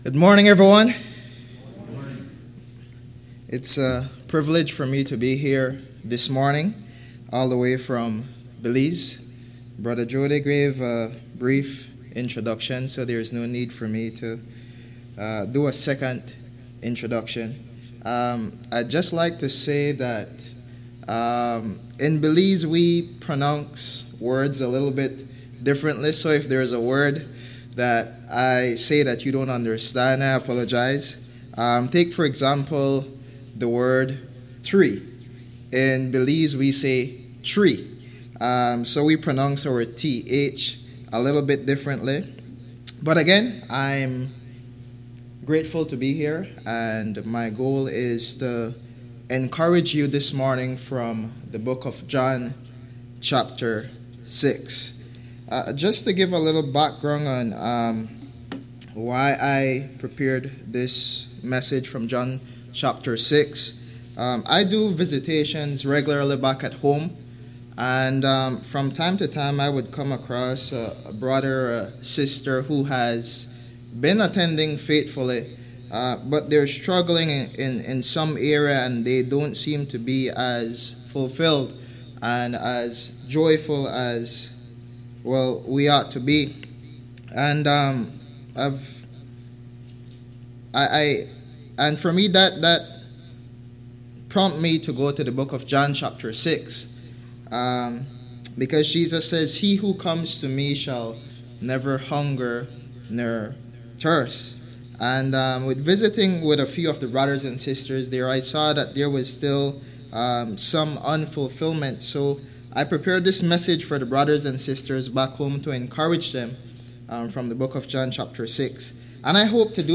Passage: John 6:1-71 Service Type: AM Worship Download Files Notes Topics